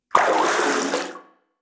WaterSplash_Out_Short1.wav